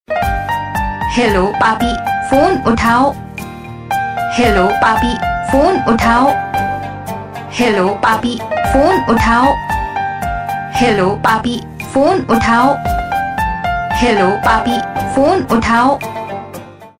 / Name Ringtones with songs